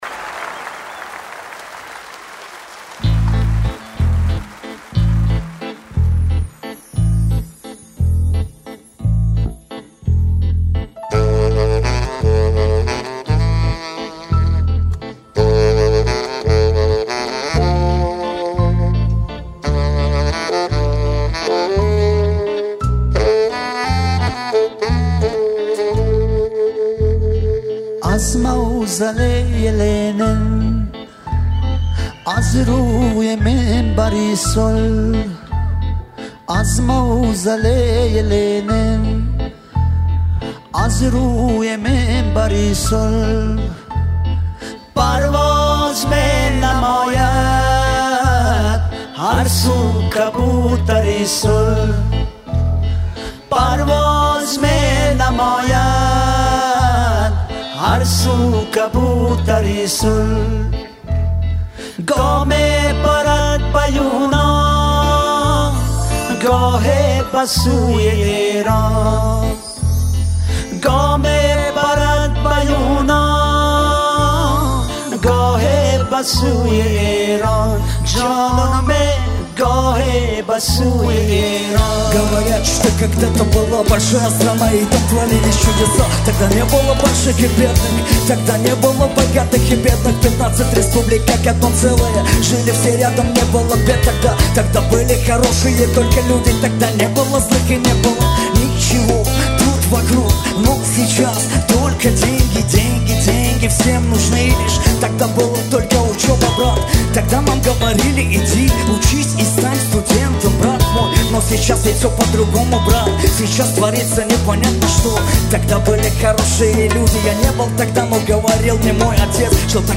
одну из детских песен пионеров Советского Таджикистана